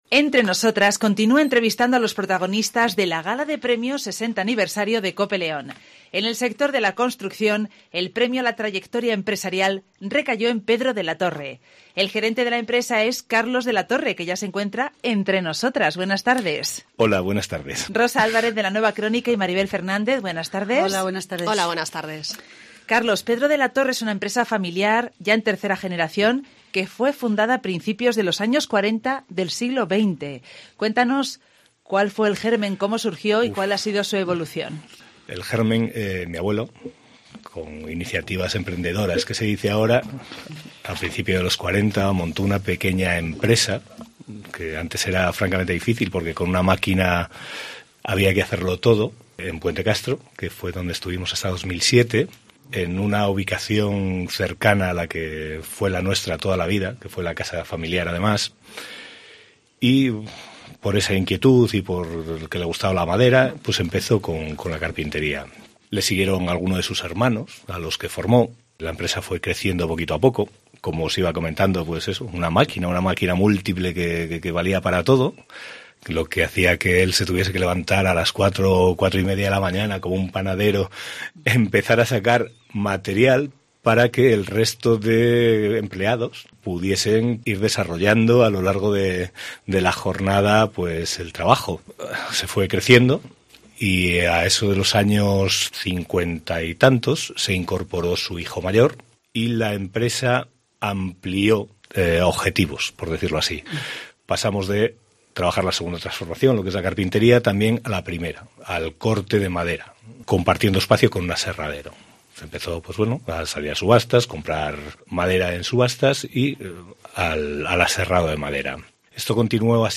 “Entre nosotras” continúa entrevistando a los protagonistas de la Gala de premios 60 aniversario de Cope León.